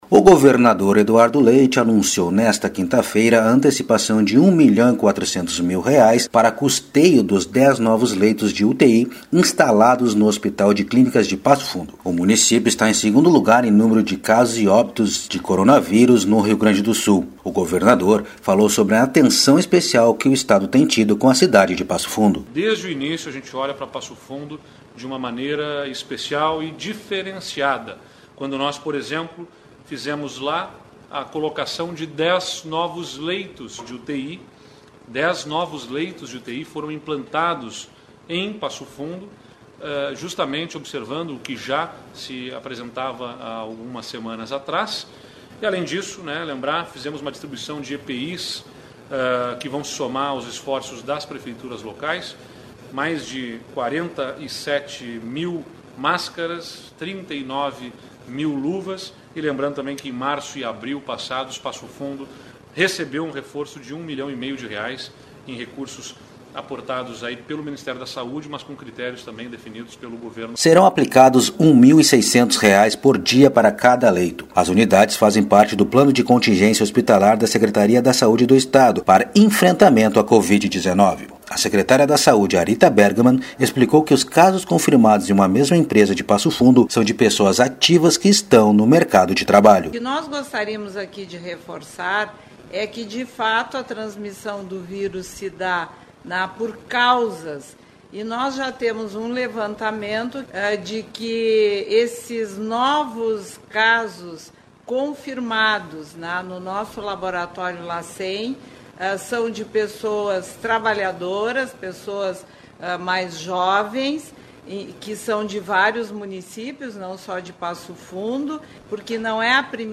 A antecipação de R$ 1,4 milhão para custeio dos 10 novos leitos de UTI instalados no Hospital de Clínicas de Passo Fundo foi anunciada pelo governador Eduardo Leite nesta quinta-feira (23), durante transmissão ao vivo pela internet.